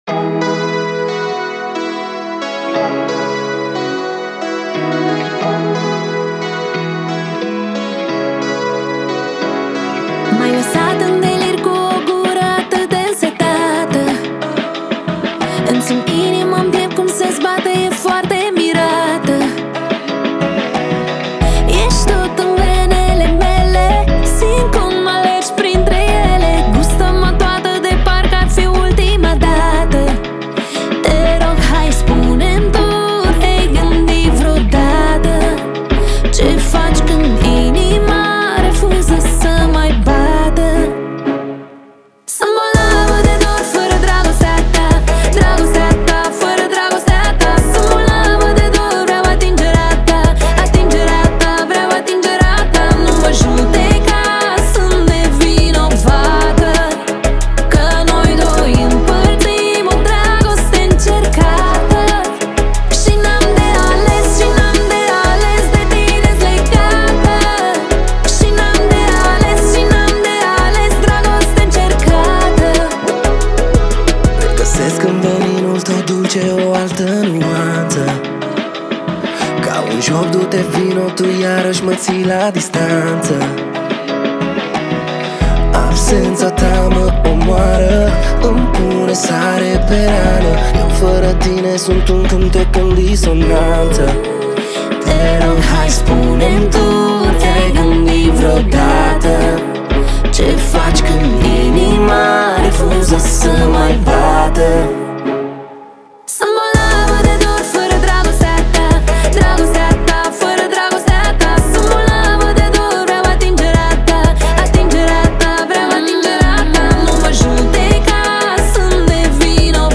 это яркая и зажигательная песня в стиле поп